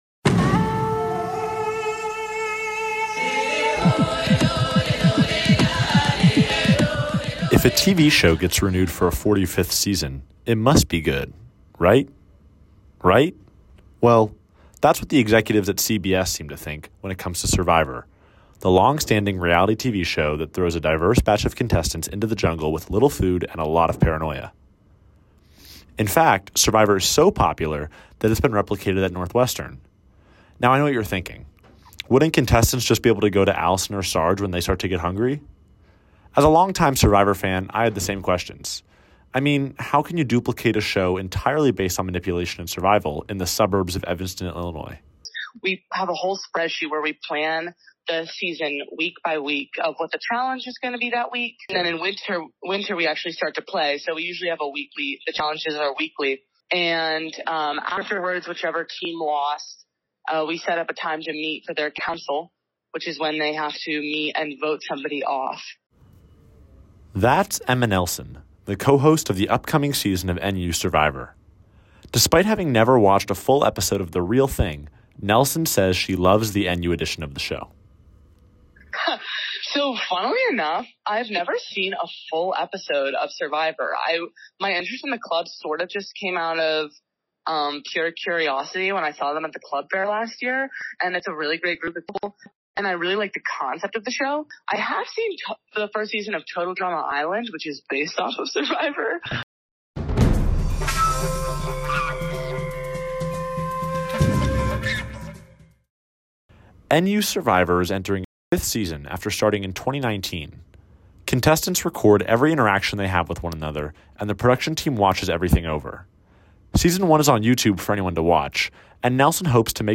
The reality TV hit show Survivor just kicked off its 45th season in Fiji, but an iteration closer to campus is preparing to start its 6th season. NU Survivor has become a staple in the Northwestern community, and what better way to find out more about the spin-off than with a few interviews.